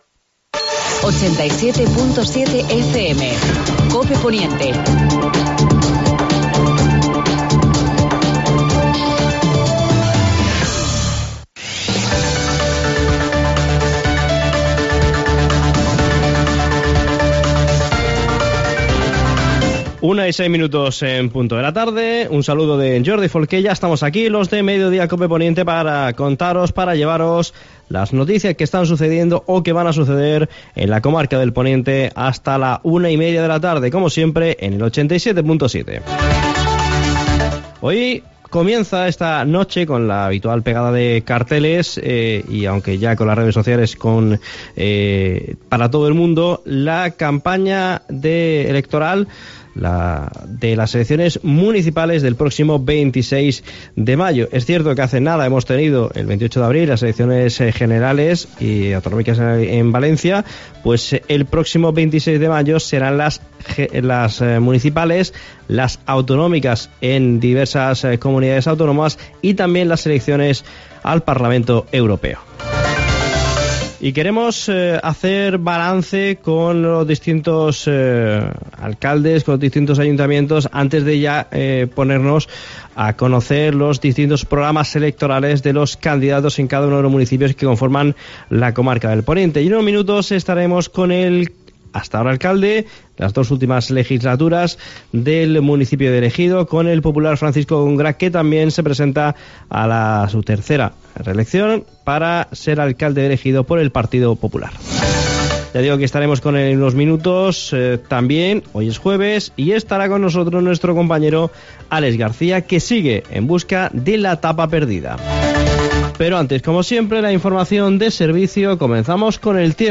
AUDIO: Actualidad en el Poniente. Entrevista a Francisco Góngora (alcalde de El Ejido y candidato del PP a la alcaldía).